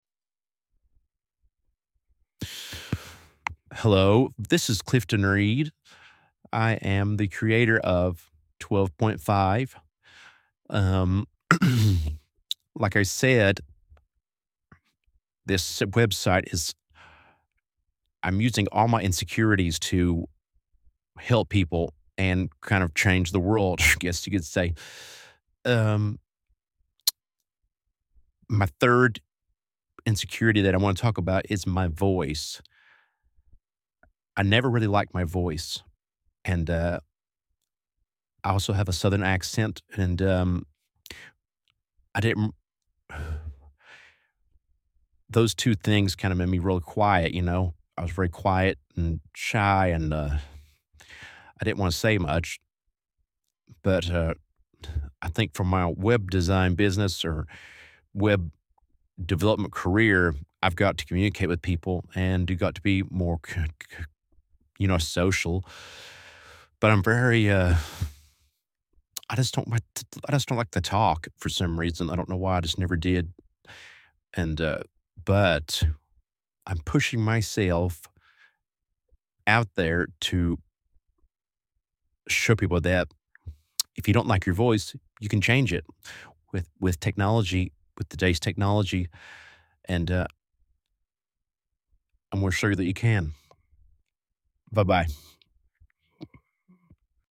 I used a service by Eleven Labs.